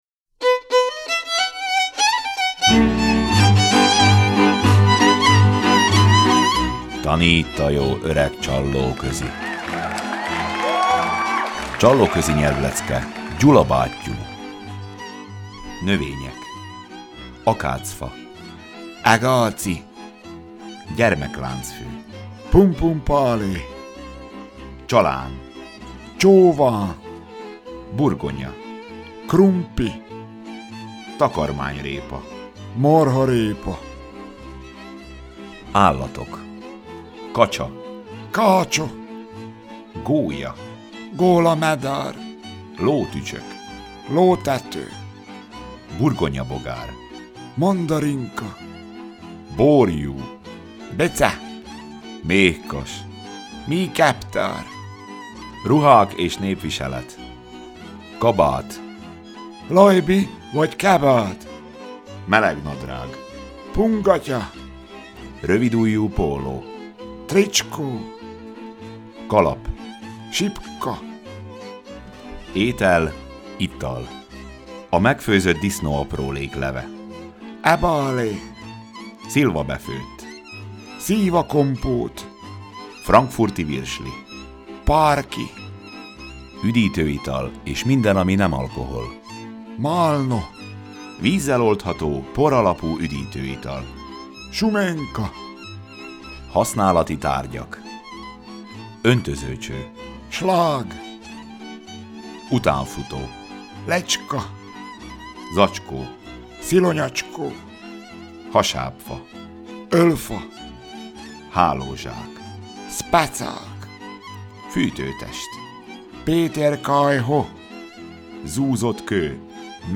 A műsor alatt elhangzik: Mezőségi népzene.